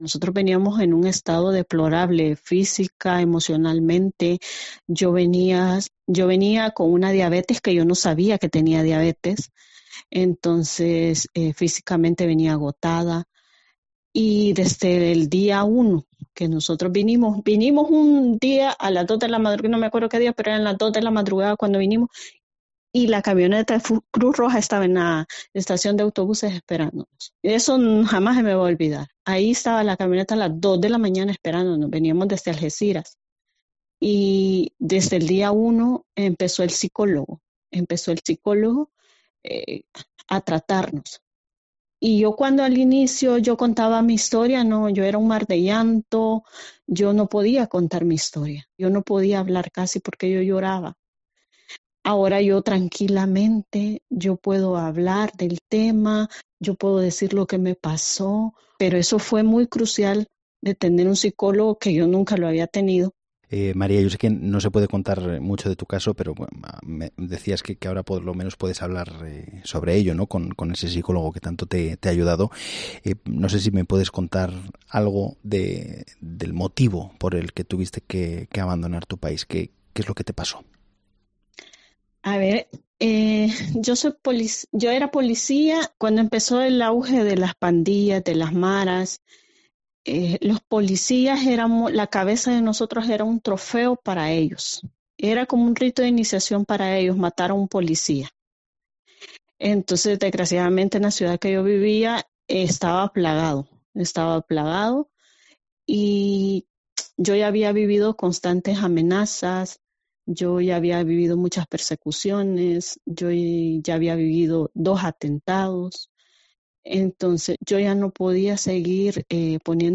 Una agente de Policía cuenta a COPE los motivos por los que tuvo que huir de Latinoamérica